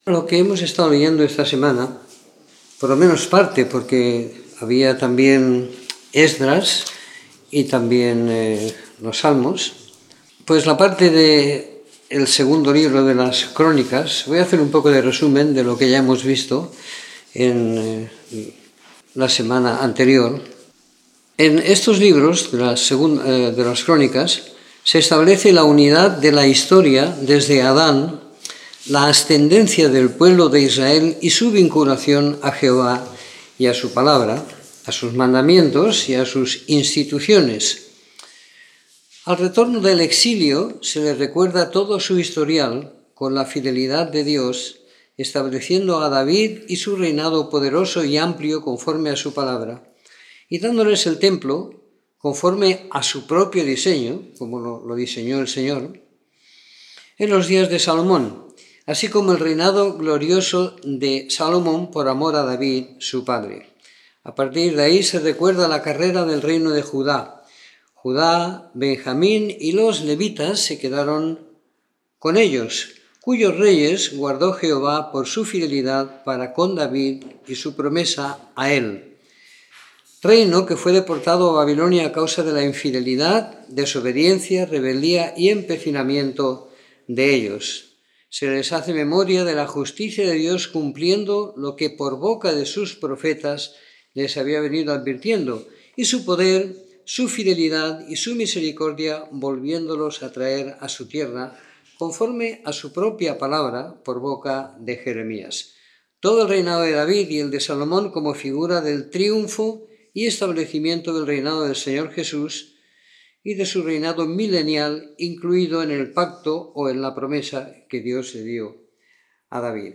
Comentario en 2º libro de Crónicas a partir del capítulo 29 y del libro de Esdras siguiendo la lectura programada para cada semana del año que tenemos en la congregación en Sant Pere de Ribes.